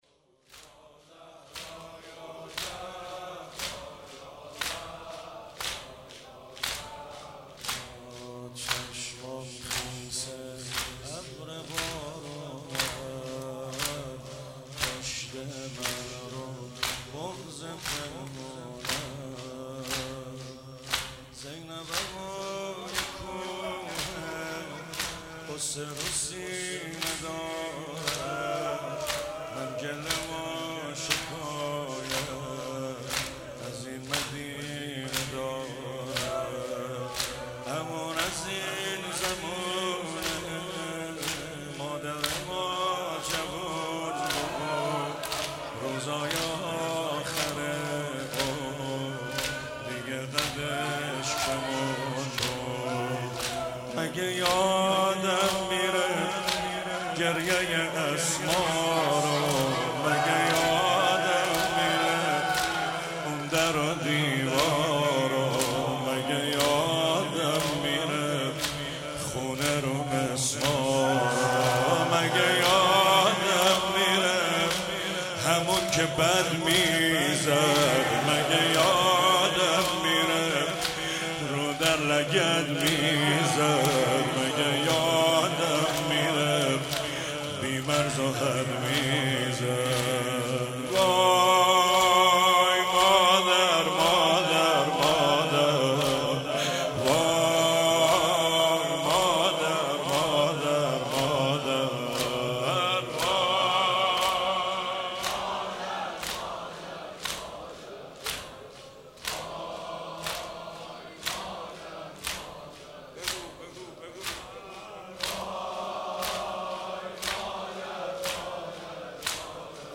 فاطمیه 97 مشهد زمینه